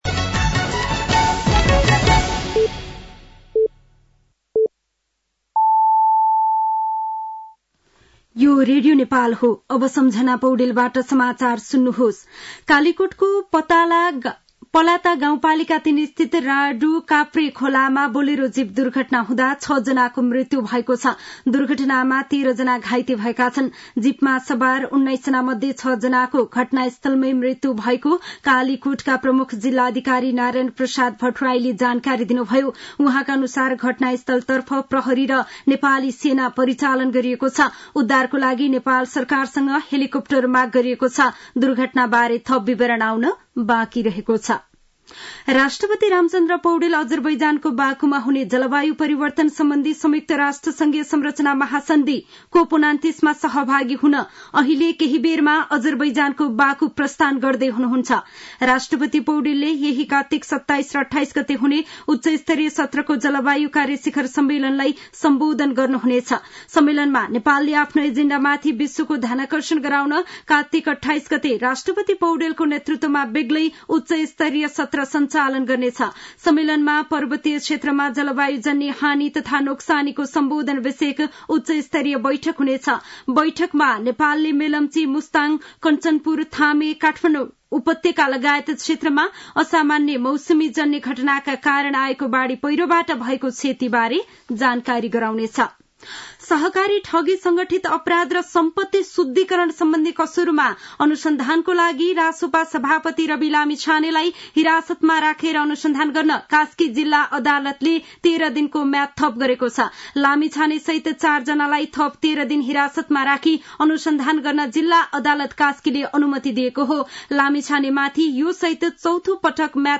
साँझ ५ बजेको नेपाली समाचार : २६ कार्तिक , २०८१
5-pm-news-1.mp3